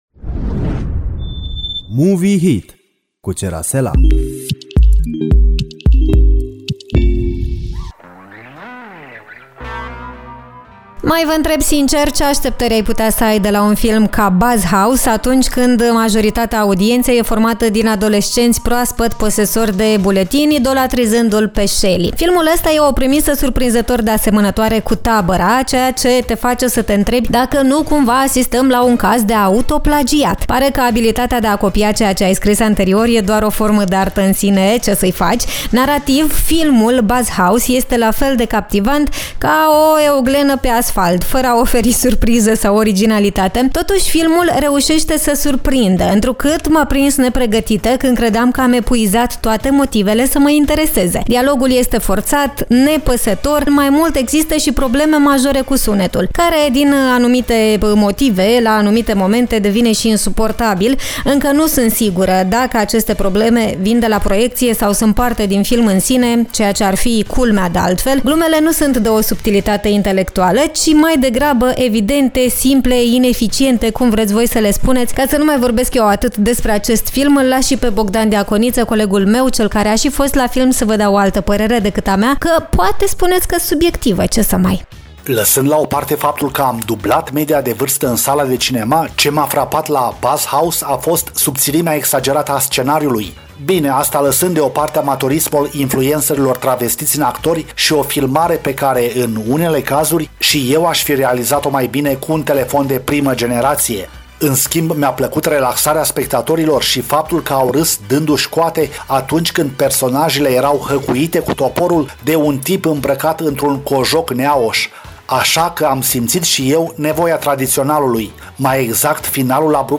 De ce nu merită să vedeți filmul la cinema vă spun colegii noștri, în argumente duble.